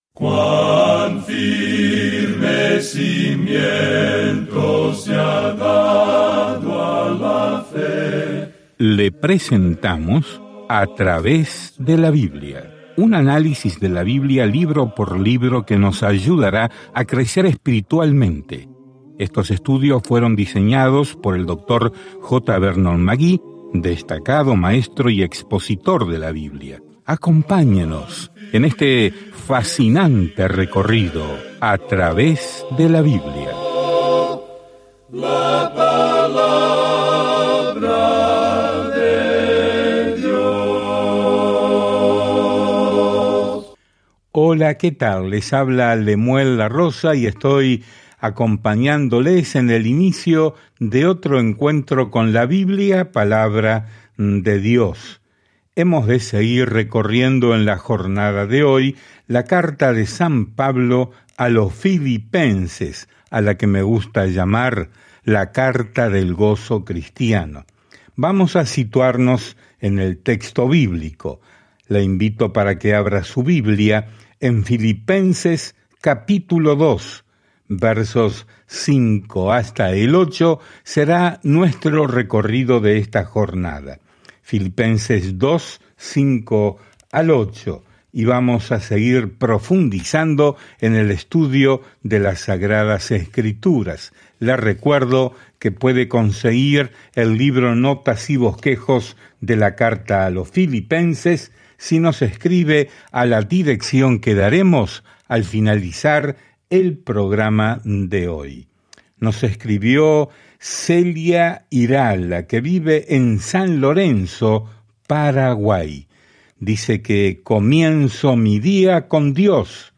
Viaja diariamente a través de Filipenses mientras escuchas el estudio en audio y lees versículos seleccionados de la palabra de Dios.